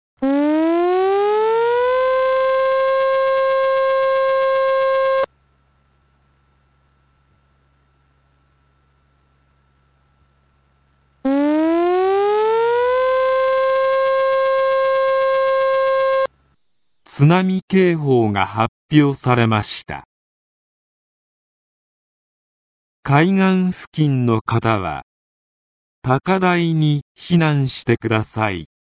全国瞬時警報システム（J－ALERT）による放送例
津波警報（MP3：141KB） (音声ファイル: 141.0KB)